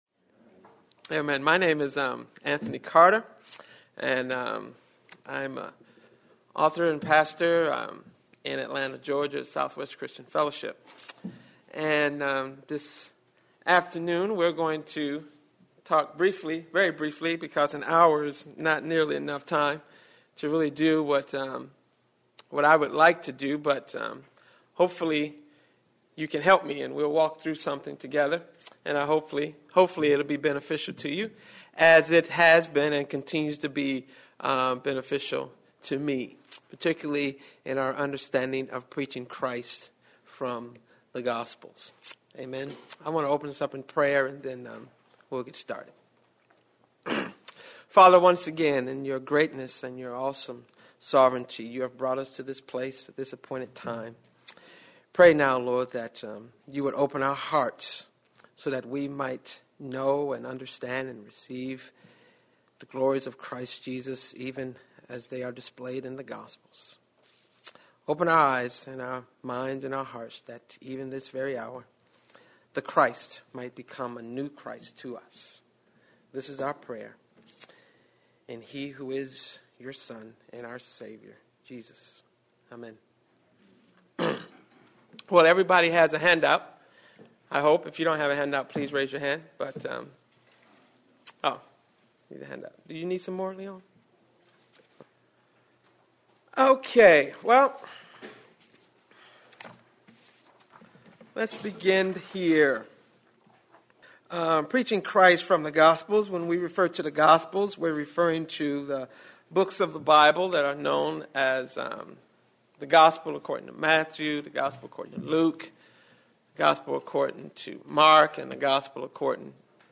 Presented at the 2008 Calvin Symposium on Worship.